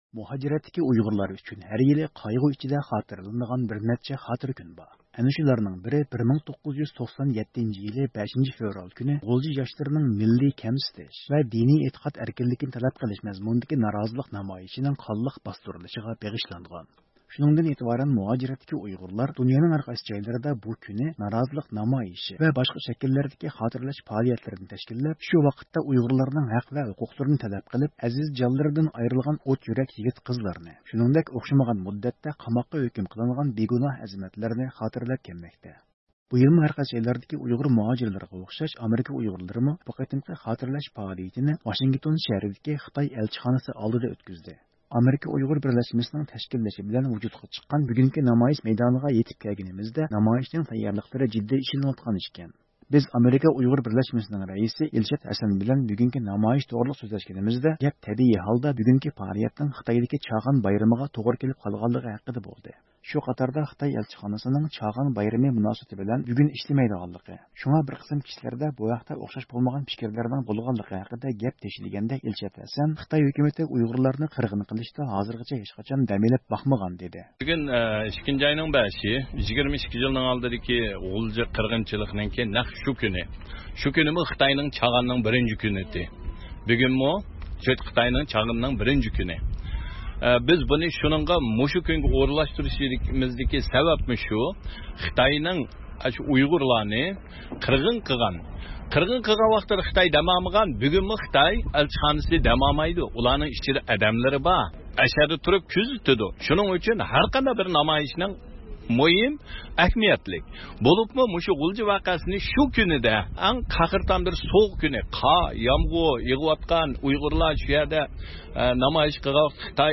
شۇنىڭدىن كېيىن نامايىش مەيدانىغا توپلانغان ئۇيغۇر جامائىتى ئۇيغۇرلارغا ھۆرلۈك تەلەپ قىلىش، خىتاي ھۆكۈمىتىنى ئەيىبلەش مەزمۇنىدىكى شوئار سادالىرى بىلەن خىتاي ئەلچىخانىسى ئاسمىنىنى لەرزىگە كەلتۈردى.